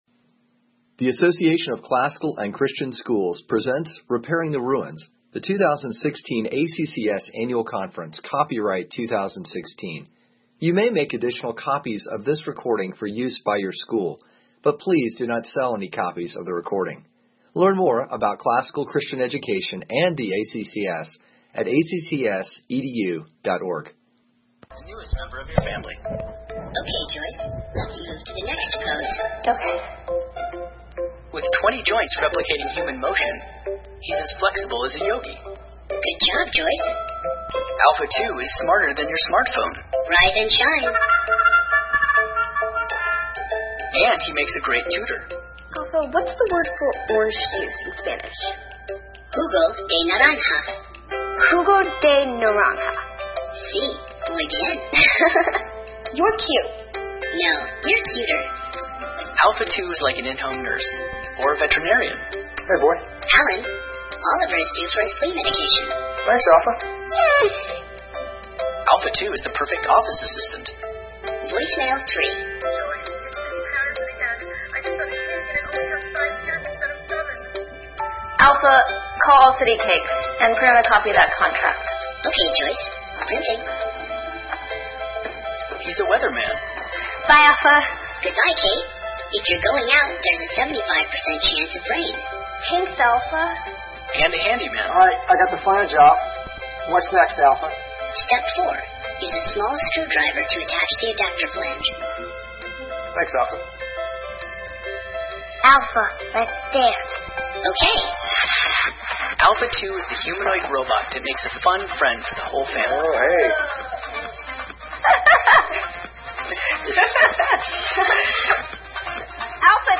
2016 Leaders Day Talk | 41:09:00 | Fundraising & Development, Leadership & Strategic, Marketing & Growth
This workshop will expose you to cutting edge developments and provide principles for evaluating options.